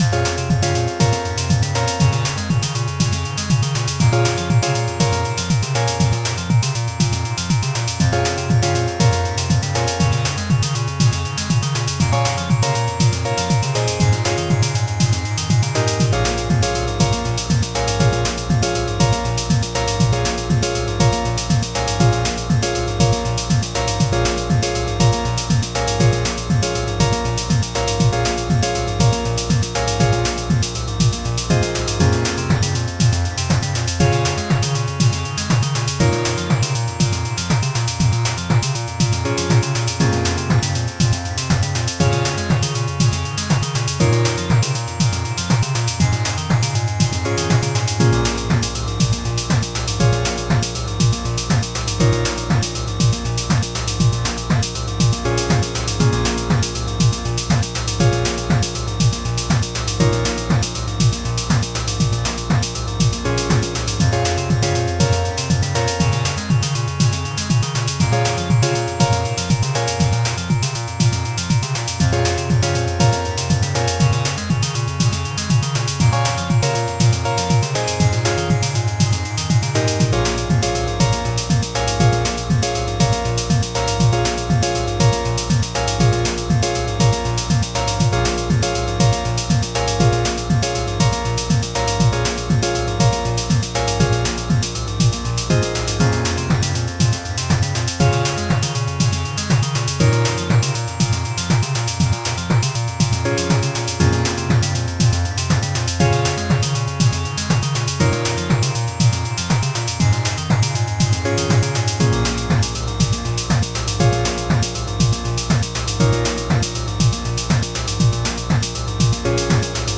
Something something chiptune disco